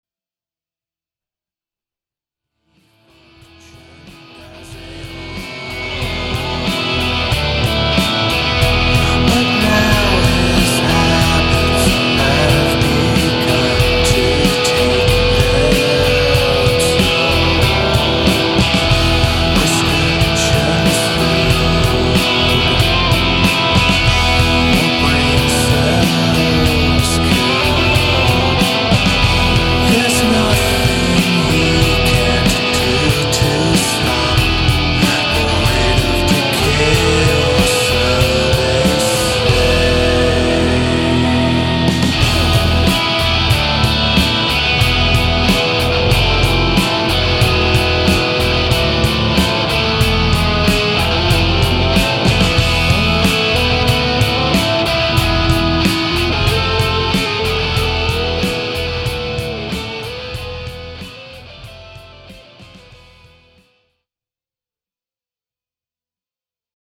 Recorded at Diamond Mine Studio